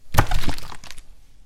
描述：记录了一瓶水被扔到我的胸前或被扔到一个装有更多瓶子和水的桶里。
标签： 命中 飞溅
声道立体声